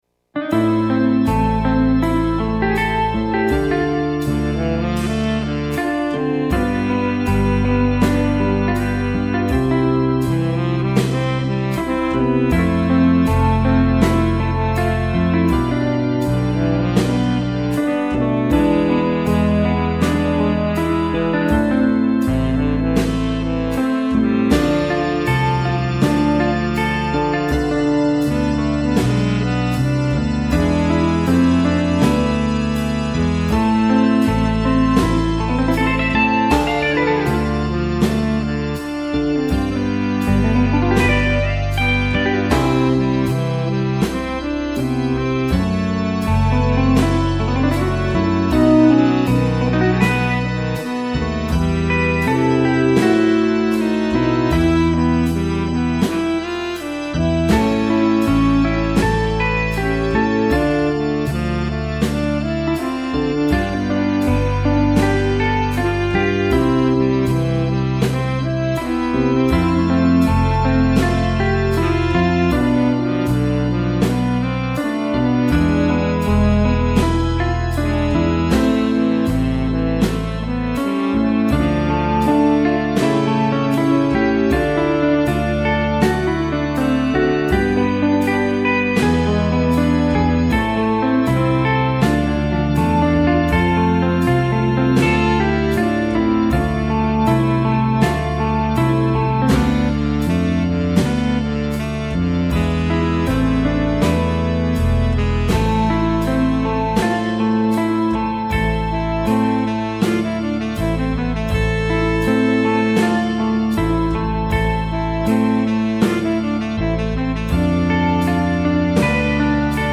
Instrumentation: Ten. Sax, Solo Gtr,
(opt.Rhythm Gtr)
Keyboards, Bass, Drums
A light jazz flavoured arrangement with
good opportunities for improvisation.